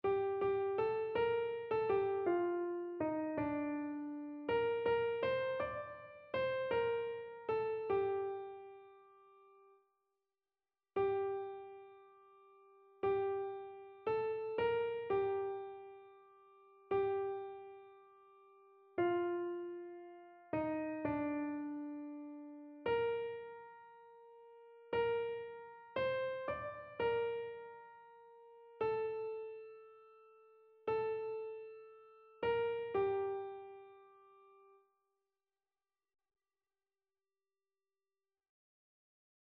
annee-b-temps-ordinaire-29e-dimanche-psaume-32-soprano.mp3